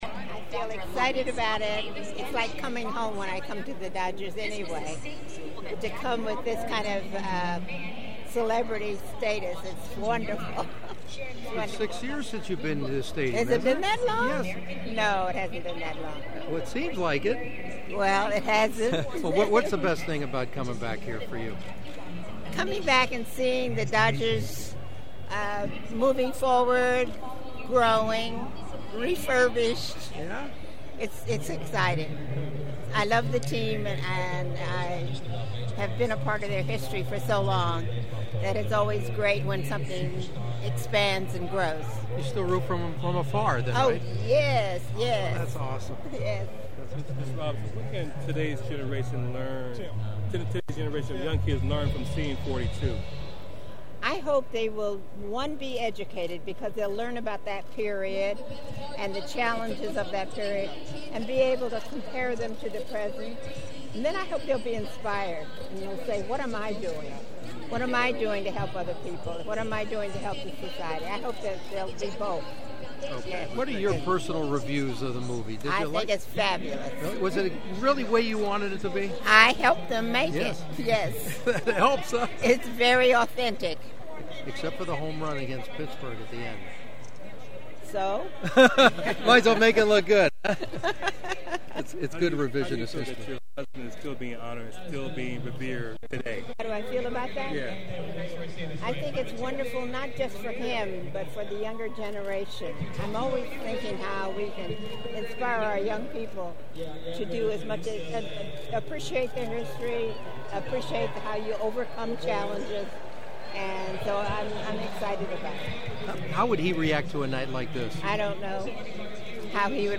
I hadn’t seen Rachel Robinson in 6 years (which I reminded her in our group interview) and she still looks amazing at the spry young age of 90. And she was in a nice talkative mood especially when I asked her about the new movie ’42’…